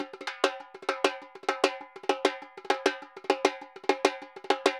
Repique Candombe 100_2.wav